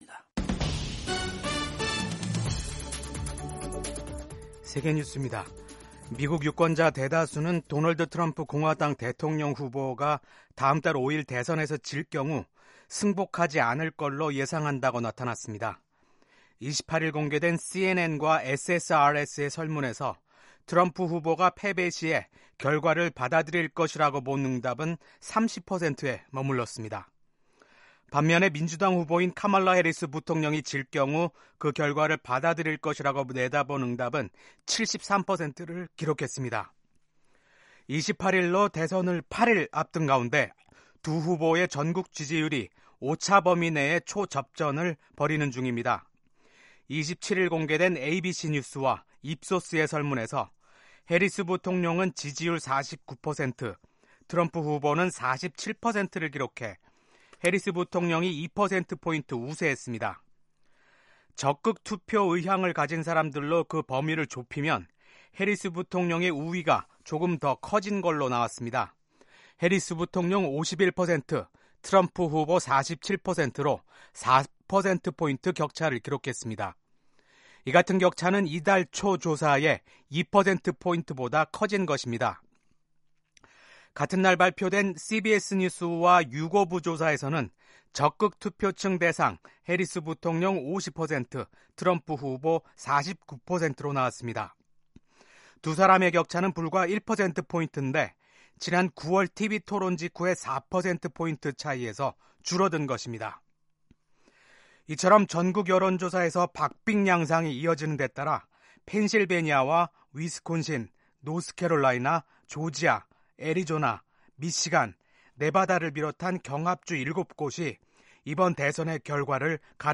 세계 뉴스와 함께 미국의 모든 것을 소개하는 '생방송 여기는 워싱턴입니다', 2024년 10월 29일 아침 방송입니다. 국익에 근거해 이란에 대한 보복 방안을 정할 것이라고 이스라엘 정부가 밝혔습니다. 미국 공화당 대통령 후보인 도널드 트럼프 전 대통령과 민주당 후보 카멀라 해리스 부통령이 14일 펜실베이니아에서 각각 유세하며 상대방을 겨냥한 공방을 주고받았습니다.